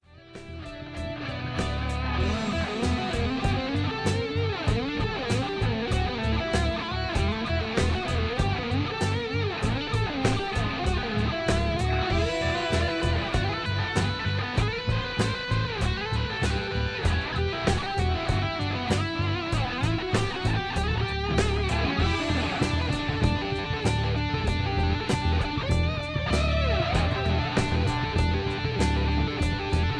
Electric Guitar instrumental single track. Home recording.
All guitars, bass and drums